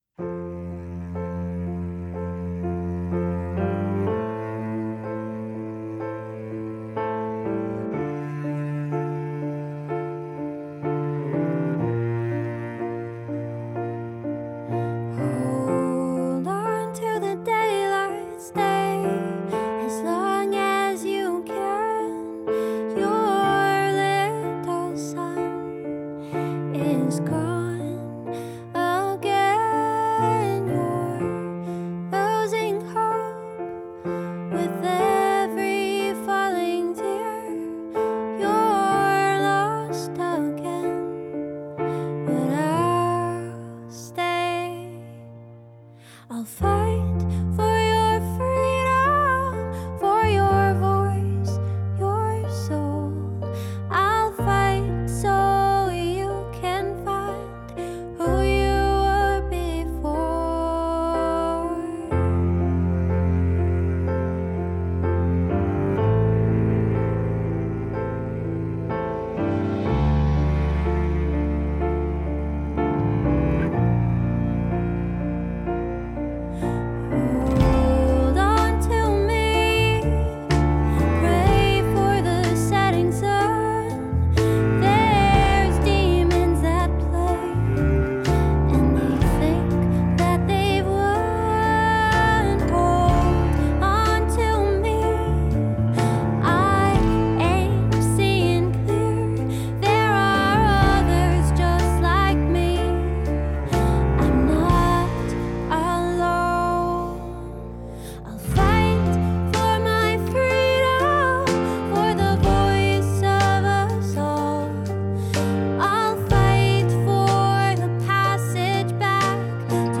Her music explores the balance of opposites; her vocals and melody are dreamy yet sorrowful, her lyrics are scornful yet optimistic. She encapsulates listeners through her haunting, lilting melodies, strong storytelling and piano accompaniment.